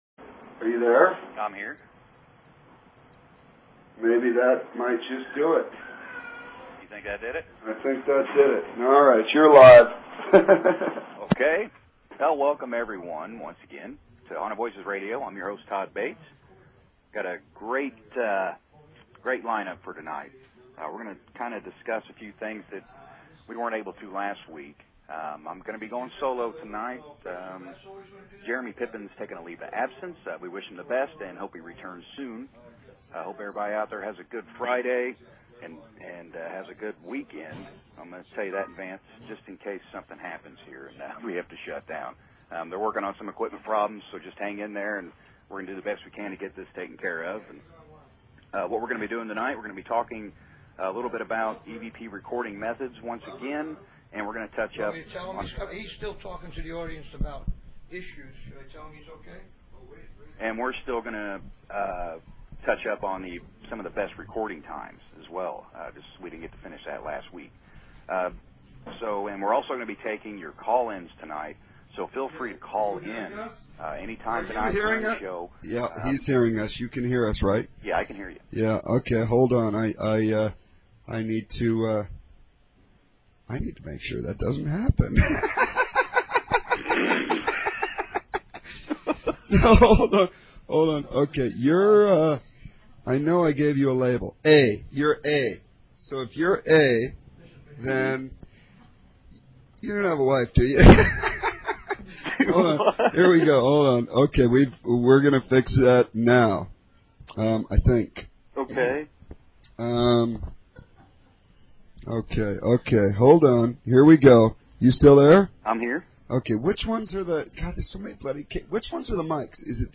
Talk Show Episode, Audio Podcast, Haunted_Voices and Courtesy of BBS Radio on , show guests , about , categorized as